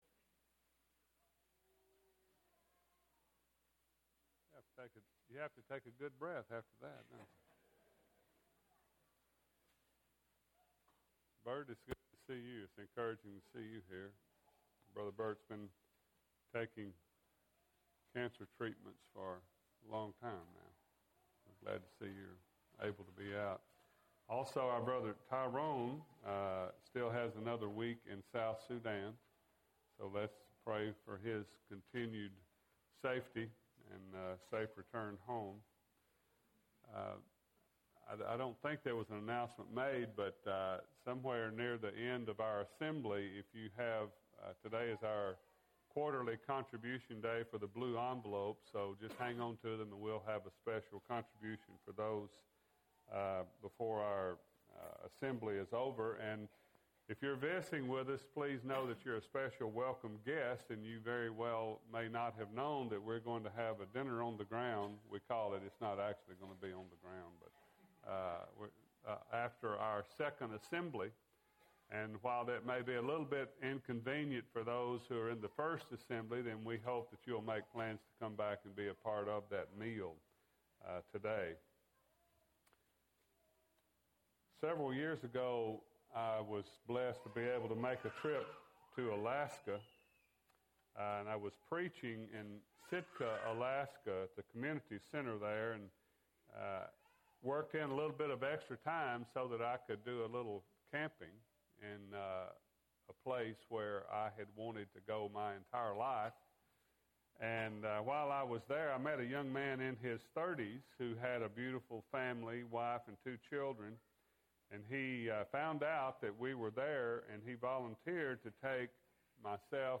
Sermon – Bible Lesson Recording